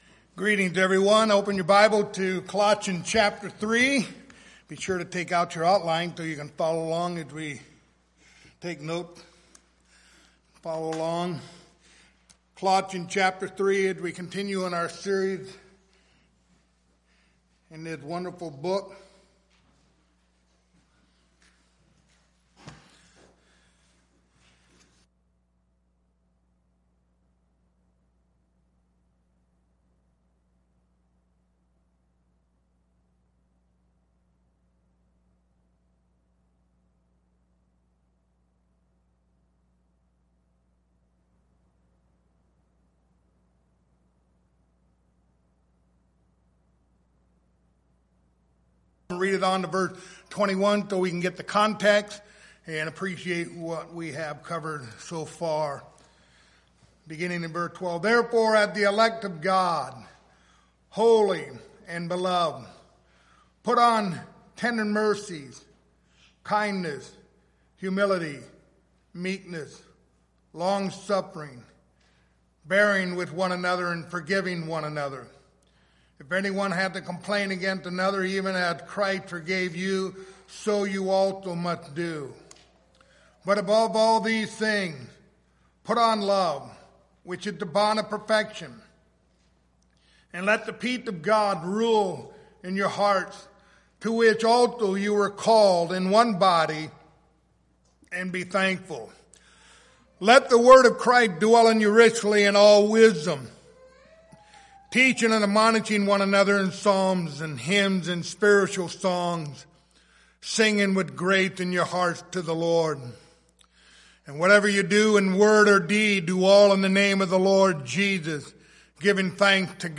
Passage: Colossians 3:16-17 Service Type: Sunday Morning